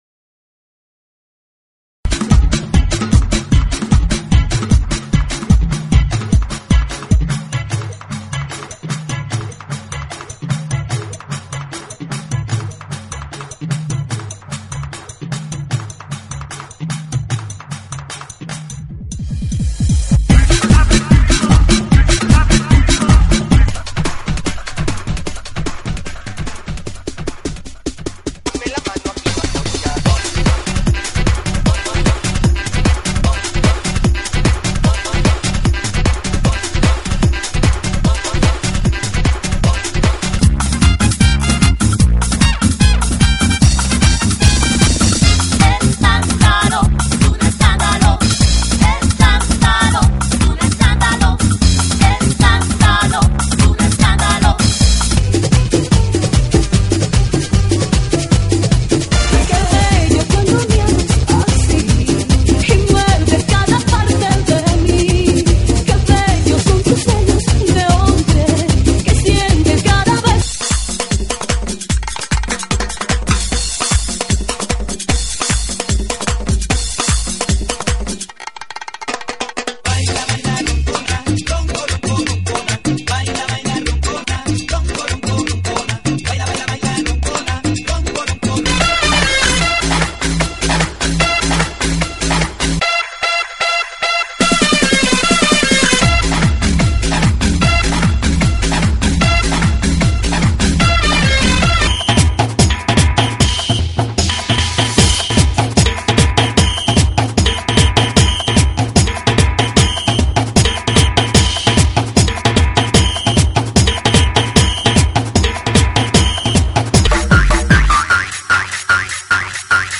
GENERO: LATINO – TRIBAL
TRIBAL LATINO,